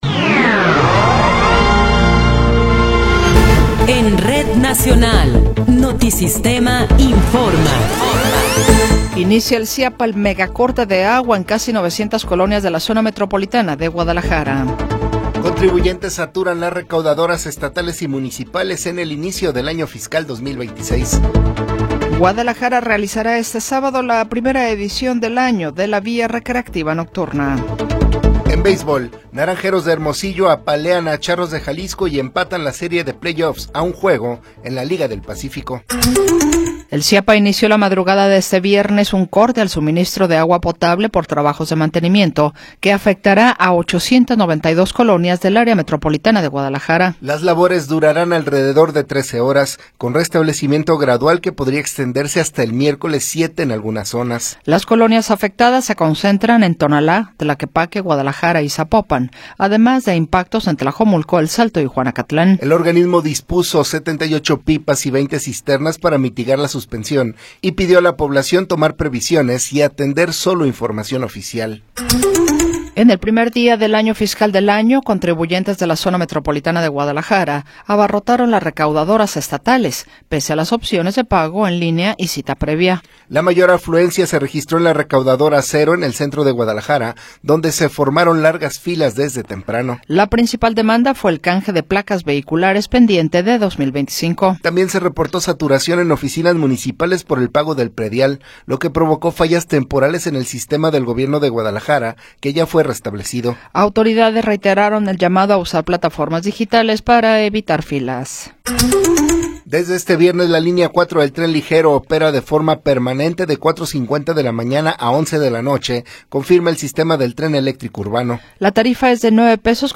Noticiero 9 hrs. – 3 de Enero de 2026
Resumen informativo Notisistema, la mejor y más completa información cada hora en la hora.